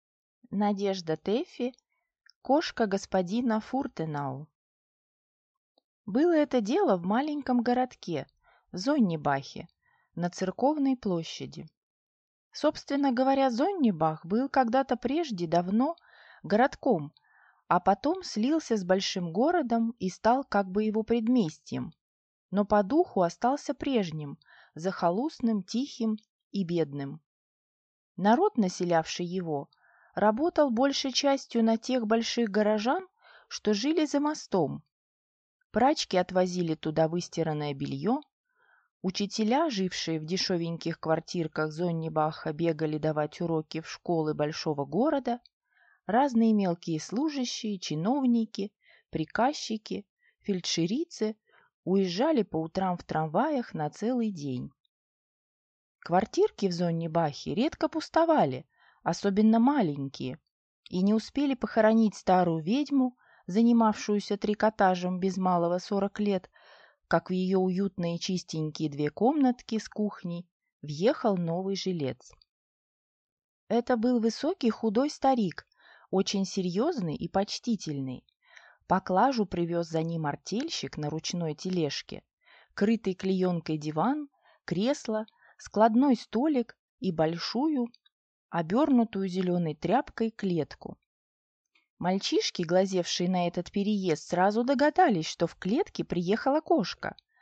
Аудиокнига Кошка господина Фуртенау | Библиотека аудиокниг